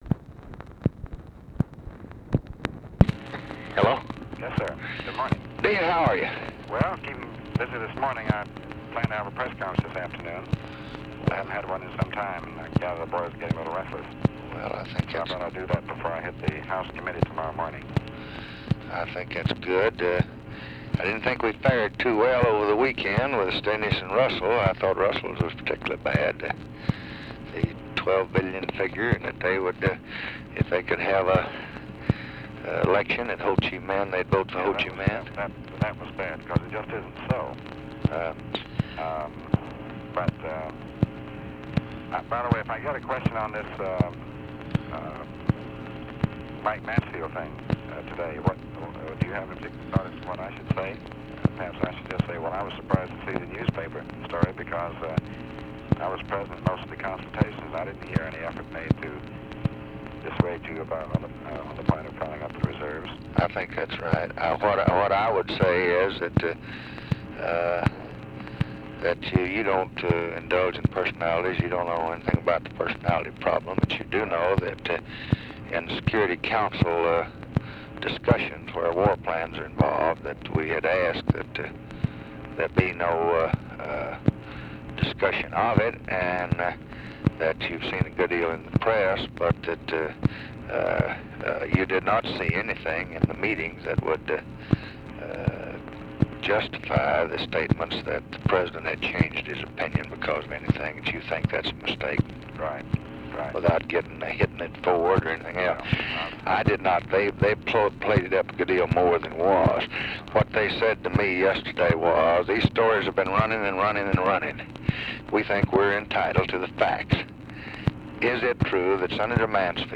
Conversation with DEAN RUSK, August 2, 1965
Secret White House Tapes